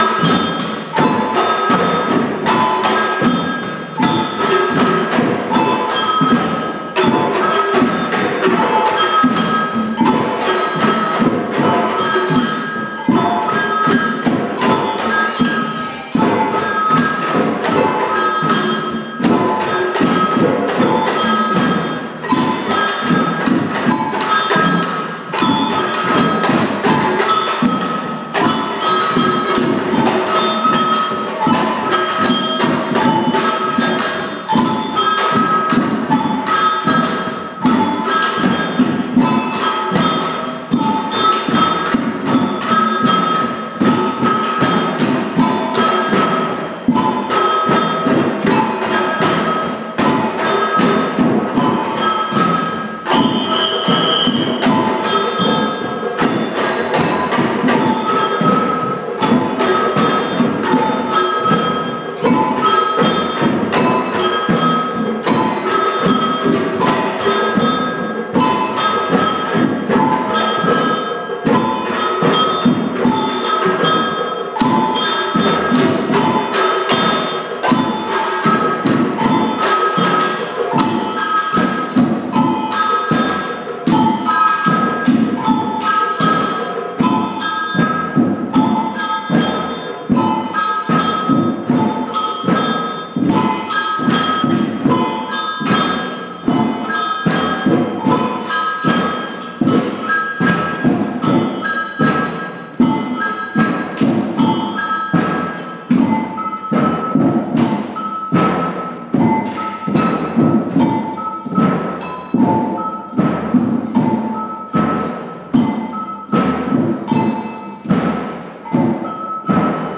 Individual parts of the band
Listen as each part of our band is halted...who is the last person playing?